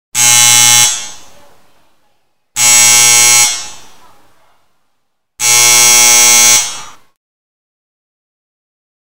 Quen está a timbrar?
17_Campainha.mp3